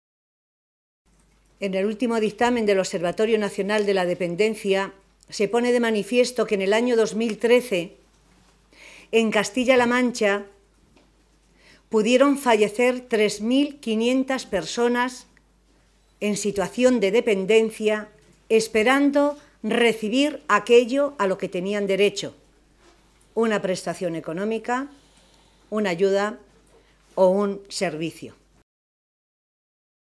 Valentín se pronunciaba de esta manera esta mañana, en Toledo, en una comparecencia ante los medios de comunicación, en la que, con los datos oficiales a fecha 28 de Febrero pasado, constataba “el desmantelamiento de la Ley de la Dependencia en la región desde que gobierna Cospedal”.